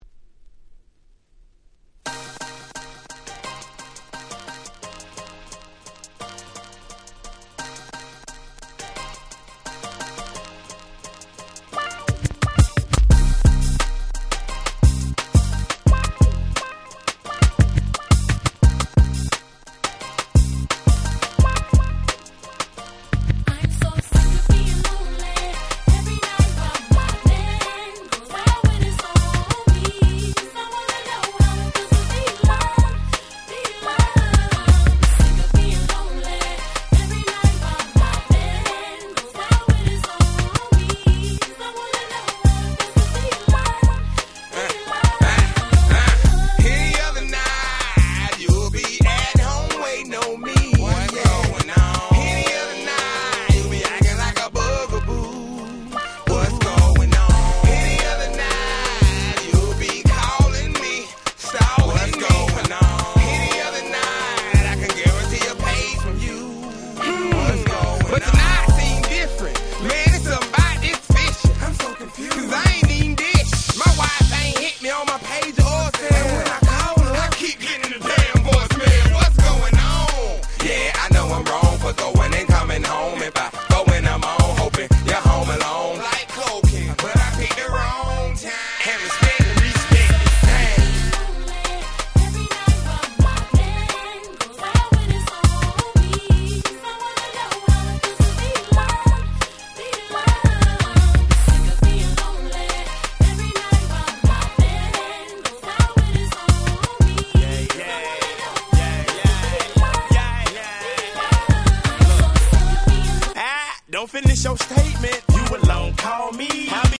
02' Smash Hit Hip Hop !!
サビに女性Vocalも絡むキャッチーな1曲！！
こういうMellowなサウス物って結構使い勝手良いんですよね〜！
South